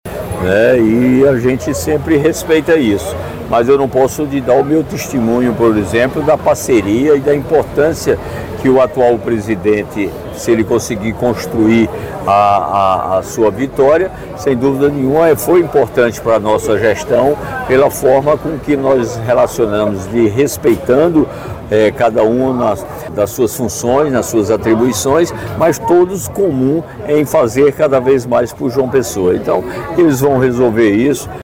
O prefeito de João Pessoa, Cícero Lucena (PP), ressaltou nesta terça-feira (05), durante entrevista ao programa Correio Debate da 98FM, a parceria que mantém com Dinho Dowsley (PSD), atual presidente da Câmara Municipal de João Pessoa (CMJP). Cícero destacou a importância de Dinho para sua gestão e comentou sobre a eleição para a mesa diretora da Câmara, afirmando que a decisão cabe aos vereadores, mas que a reeleição de Dinho seria bem-vinda.